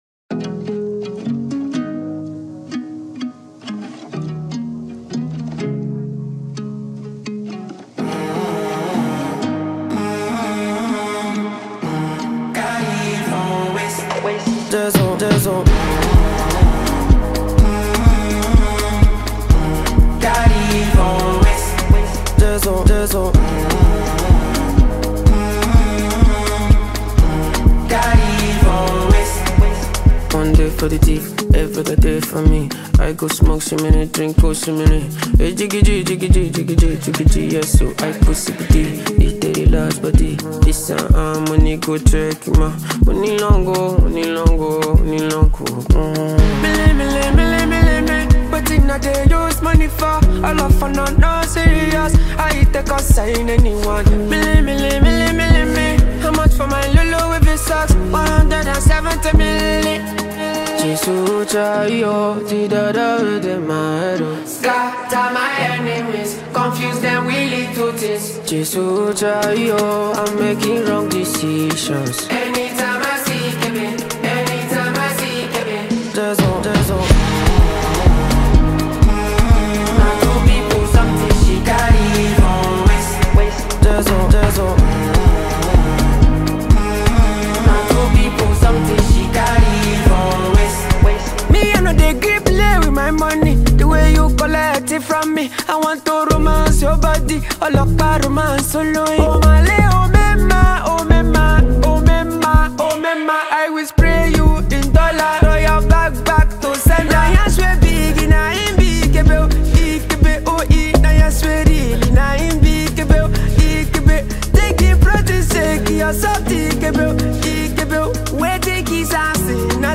blends Afrobeats with sensual rhythms
With heartfelt lyrics and a magnetic beat